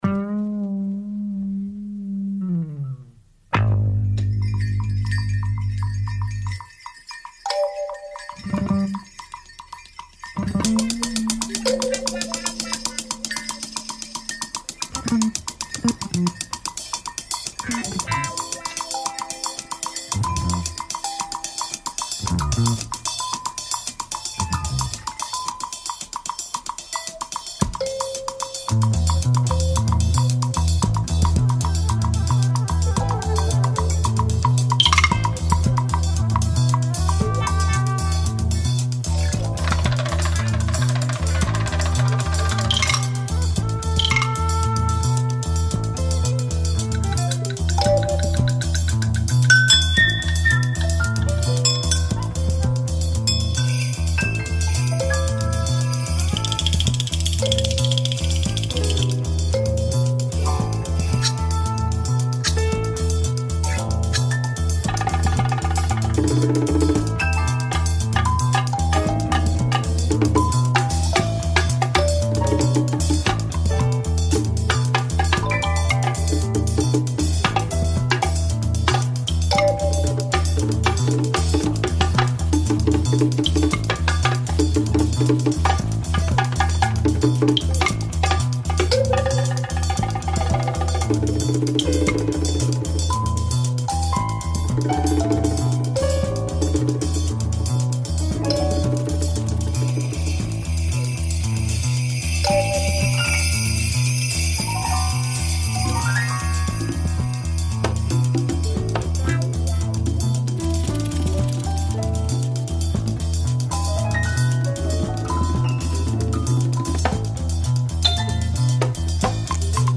Protest singer
recorded this album in Cuba
space prog folk
with its long, eerie, amazing and beautiful intro.